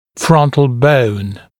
[‘frʌnt(ə)l bəun][‘франт(э)л боун]лобная кость